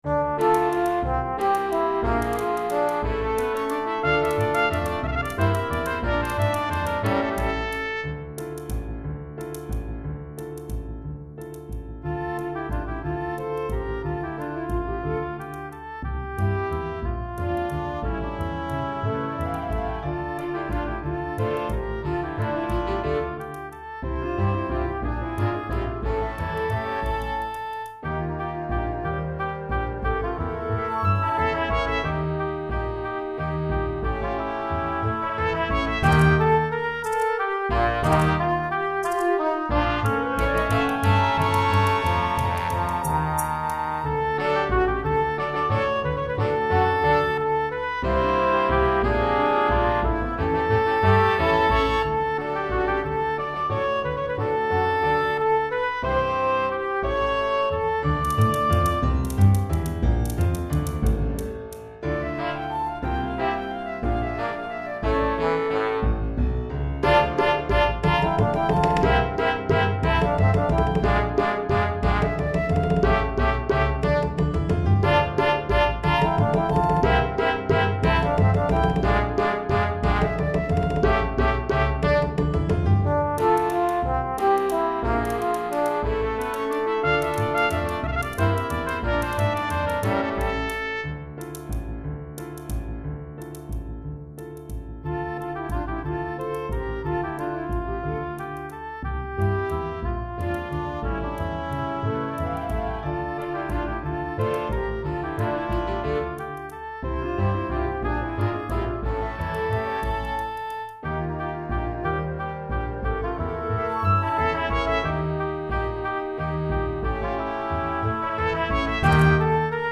Chorale d'Enfants (10 à 12 ans), Flûte Traversière